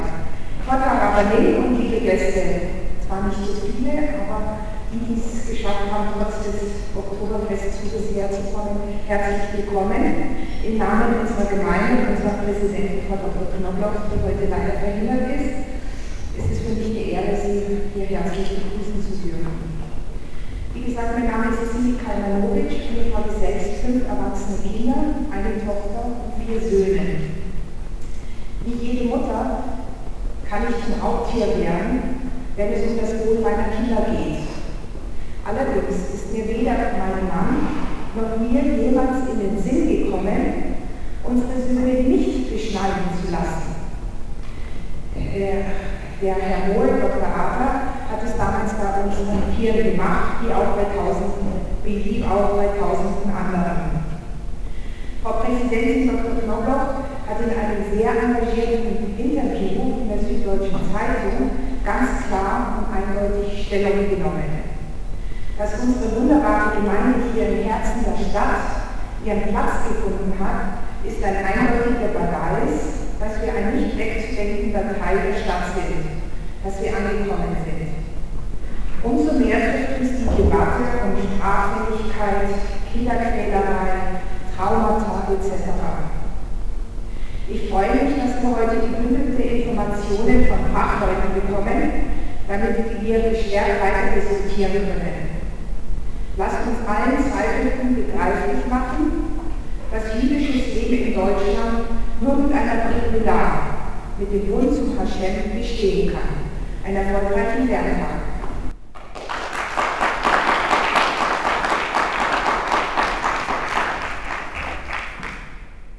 Dieser Vortrag wurde gegeben anläßlich des vom Rabbinat organisierten Lerntags zur Beschneidung, der am 23. Sep. 2012 in der Israelitischen Kultusgemeinde München statt fand.
Begrüßung